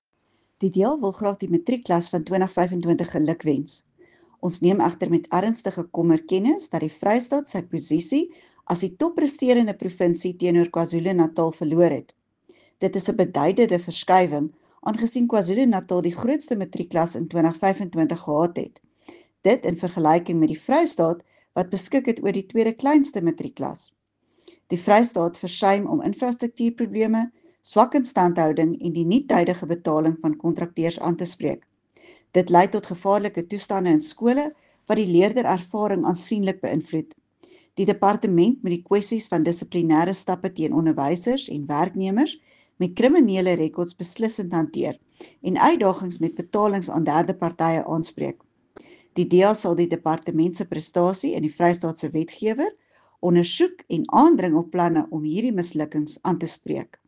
Afrikaans soundbites by Dulandi Leech MPL and